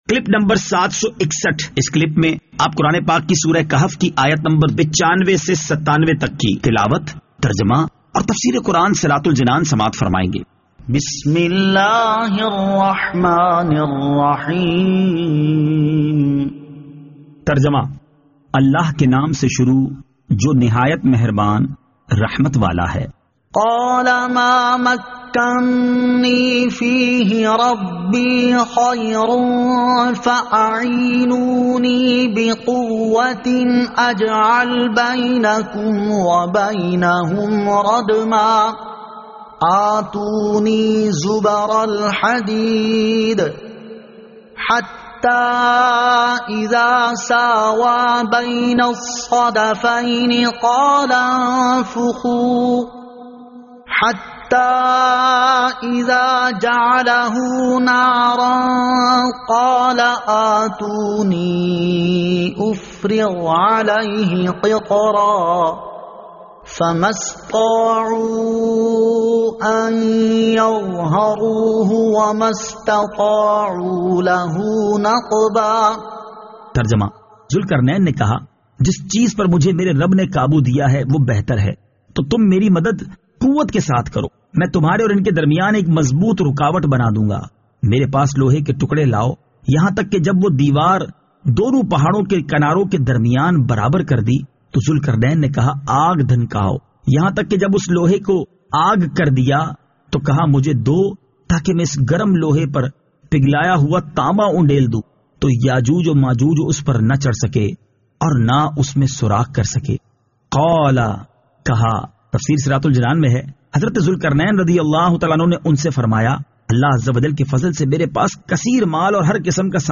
Surah Al-Kahf Ayat 95 To 97 Tilawat , Tarjama , Tafseer